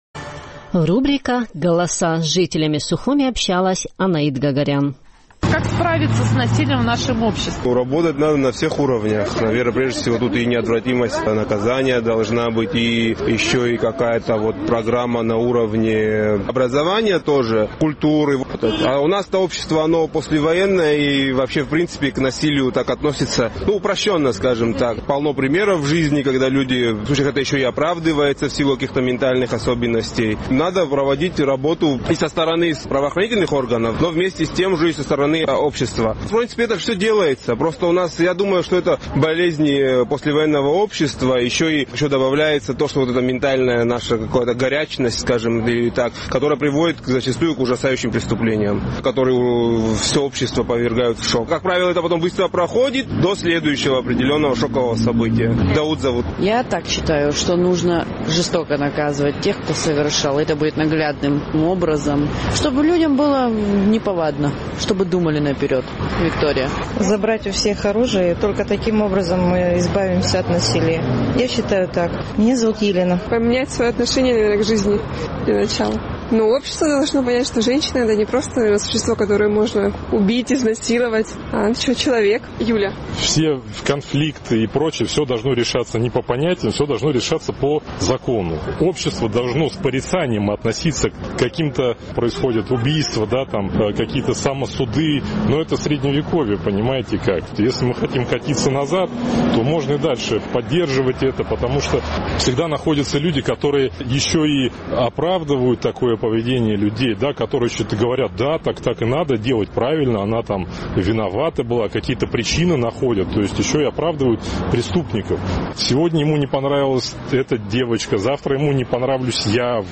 Наш сухумский корреспондент спрашивала у местных жителей, как, по их мнению, можно справиться с насилием в абхазском обществе.